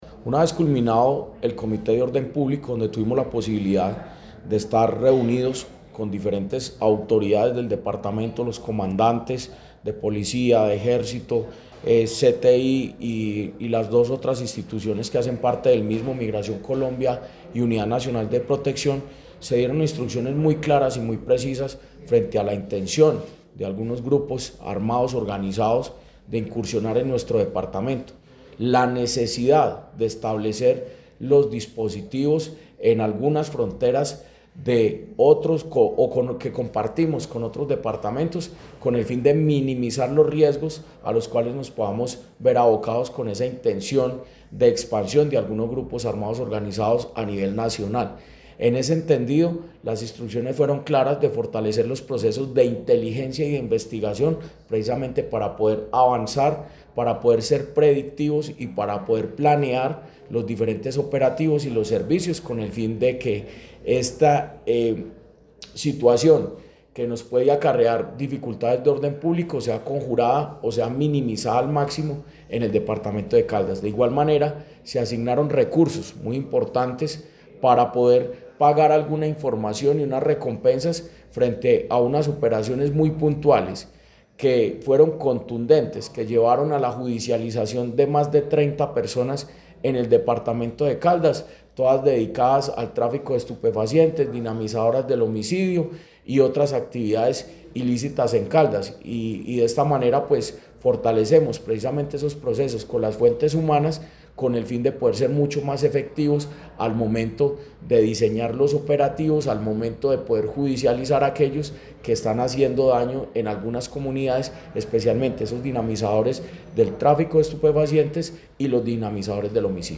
El anuncio fue hecho por el secretario de Gobierno de Caldas, Jorge Andrés Gómez Escudero, durante la realización del Comité de Orden Público que contó con representantes de la Policía Nacional, el Ejército, la Fiscalía General de la Nación, Migración Colombia y la Unidad Nacional de Protección.
Jorge Andrés Gómez Escudero, secretario de Gobierno de Caldas.
Jorge-Andres-Gomez-Escudero-secretario-de-Gobierno-de-Caldas-Comite-de-Orden-Publico.mp3